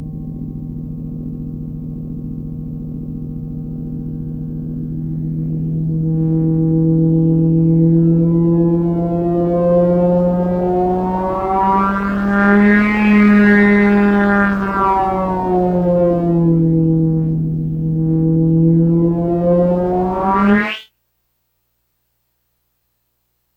Index of /90_sSampleCDs/E-MU Producer Series Vol. 3 – Hollywood Sound Effects/Science Fiction/Scanners
SCANNER 3-L.wav